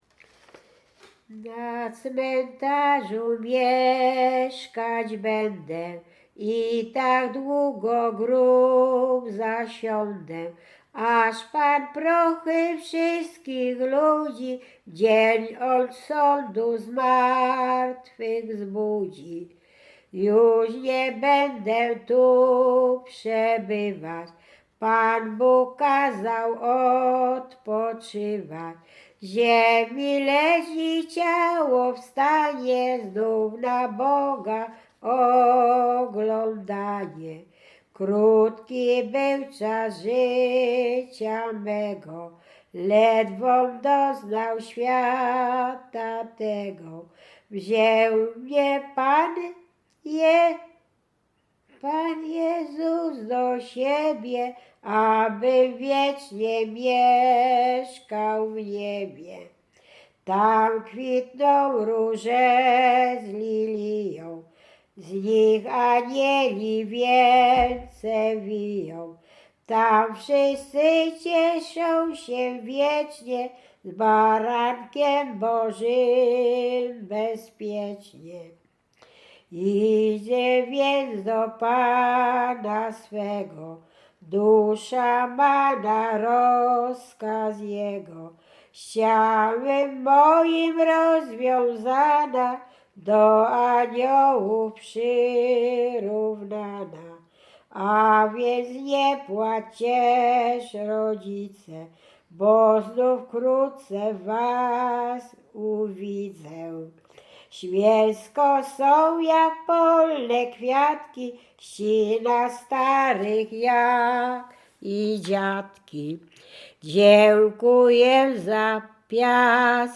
Łęczyckie
województwo łódzkie, powiat łódzki, gmina Zgierz, wieś Jasionka
Pogrzebowa
pogrzebowe nabożne katolickie do grobu